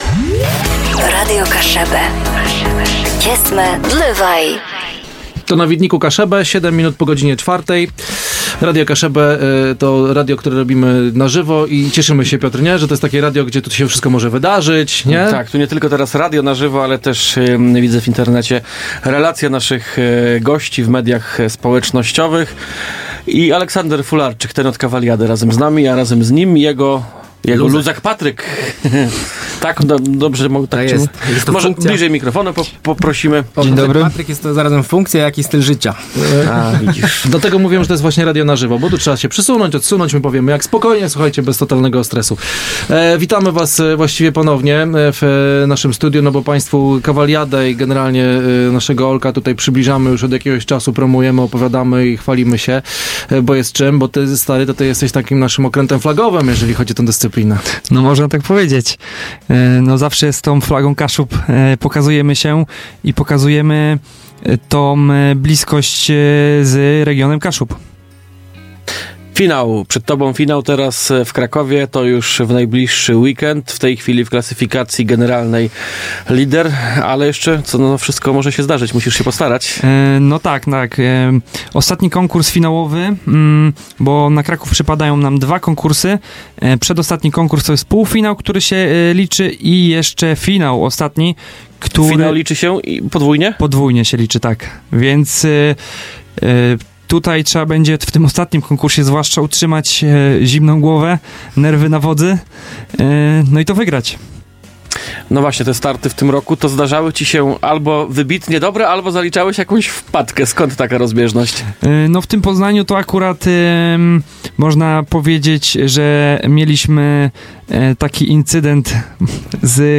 Na kilka dni przed fianałowym rozstrzygnięciem tegorocznej Cavaliady w naszym studiu